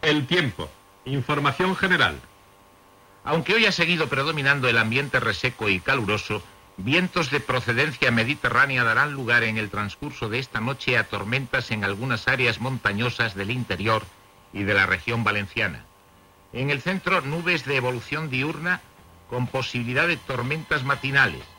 Fragment de la informació del temps
Informatiu